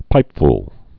(pīpfl)